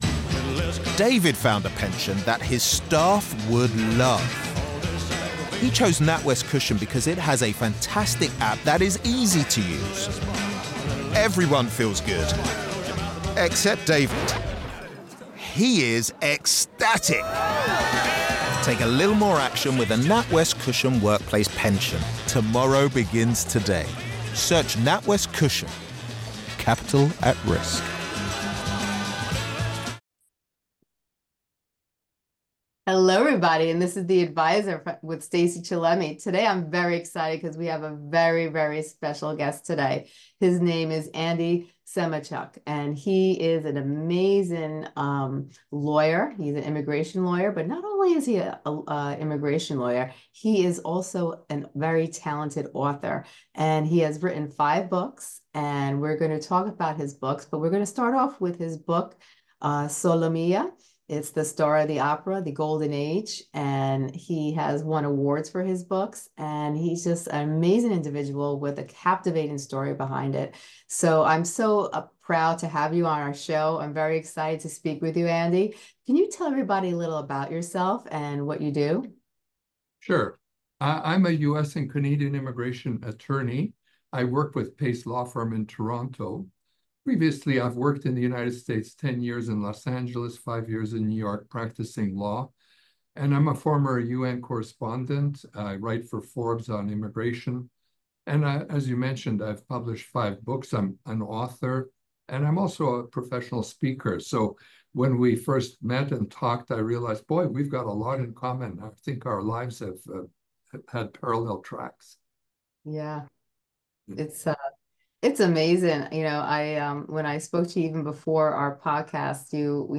an insightful conversation about the three core challenges young professionals face today—money, time, and balance.